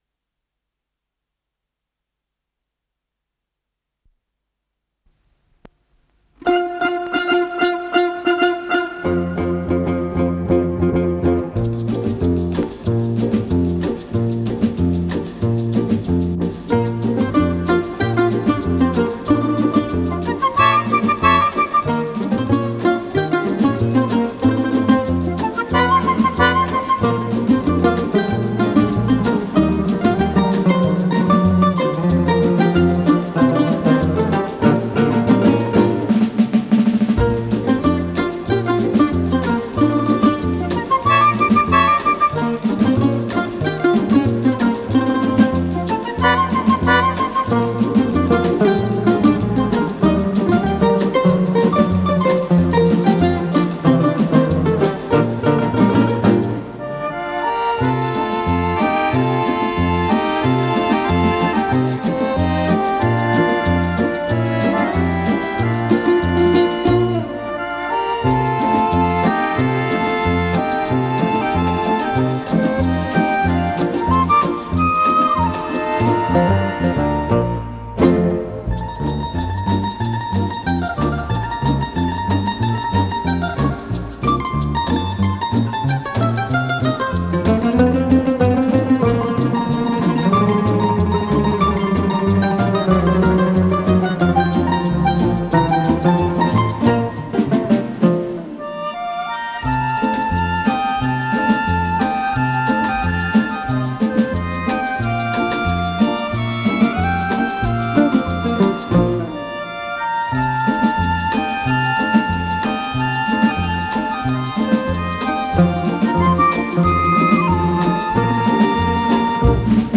с профессиональной магнитной ленты